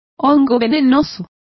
Complete with pronunciation of the translation of toadstool.